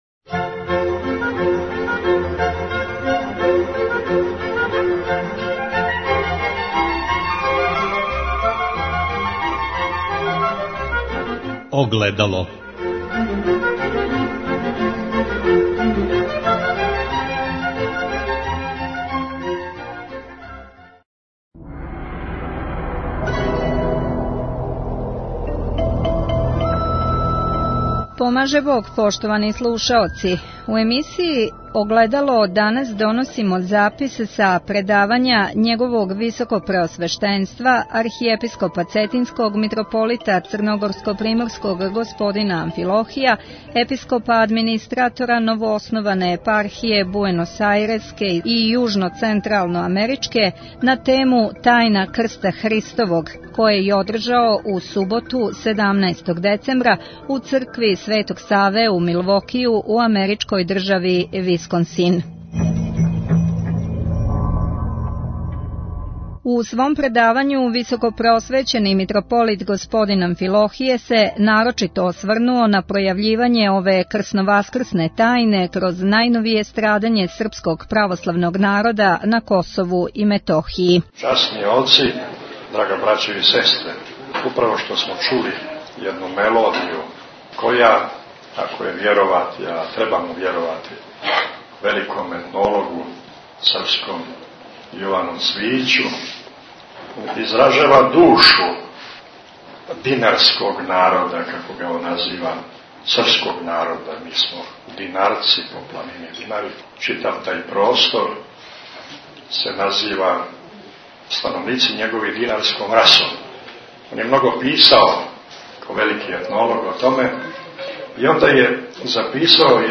Митрополит Амфилохије одржао предавање у Милвокију на тему "Тајна крста Христовог"
Запис са предавања Његовог Високопреосвештенства Архиепископа Цетињског Митрополита Црногорско-приморског Г. Амфилохија Епископа администратора новоосноване Епархије Буеносаиреске и Јужноцентралноамеричке "Тајна крста Христовог" које је одржао у суботу 17. децембра у цркви Светог Саве у Милвокију у америчкој држави Висконсин.